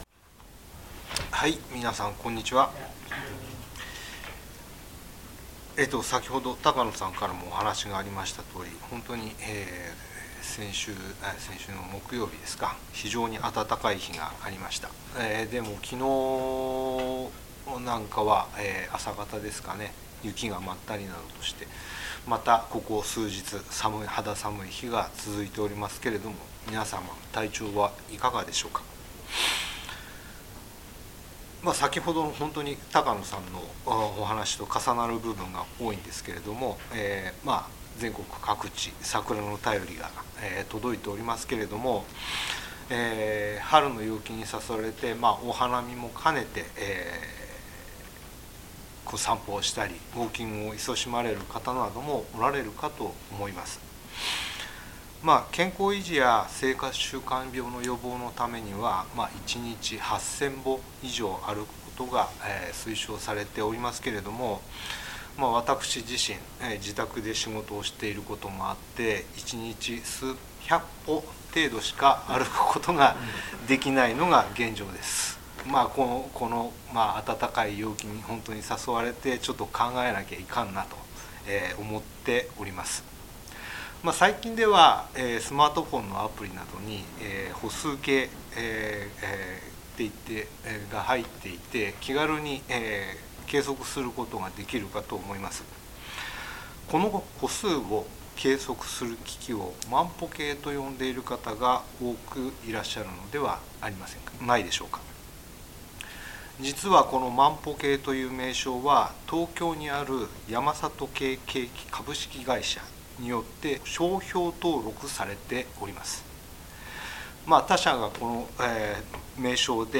聖書メッセージ No.261